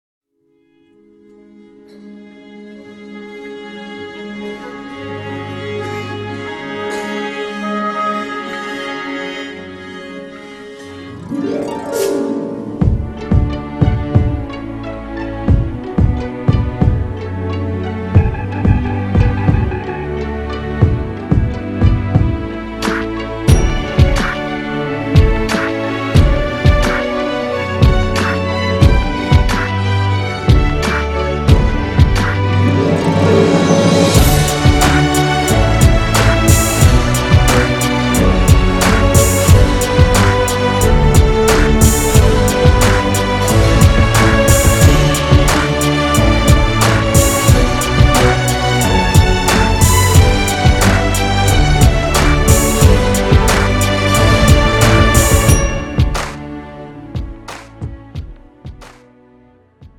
음정 (-1키)
장르 pop 구분 Pro MR